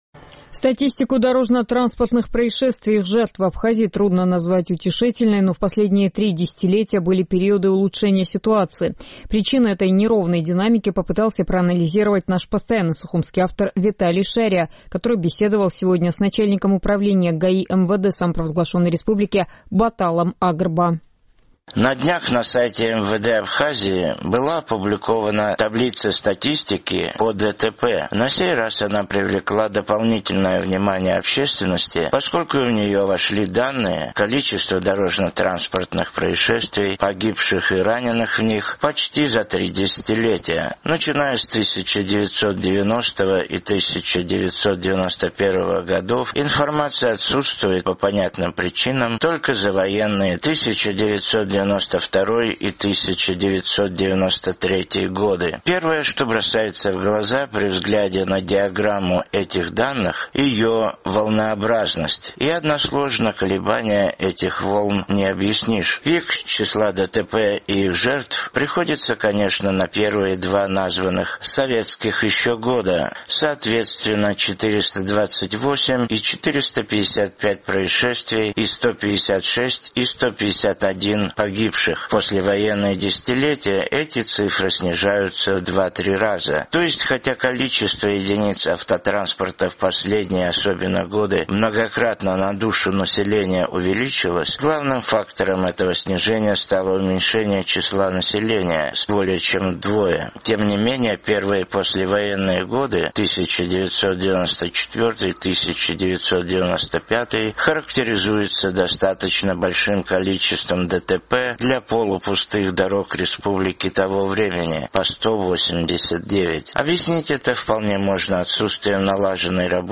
Вот как об этом говорит начальник управления ГАИ МВД РА, подполковник милиции Батал Агрба, с которым мы беседовали сегодня в его кабинете: